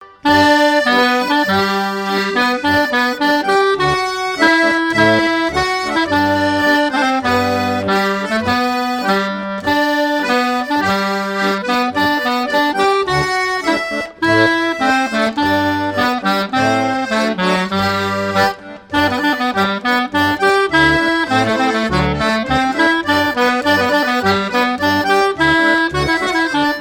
circonstance : fiançaille, noce
Pièce musicale éditée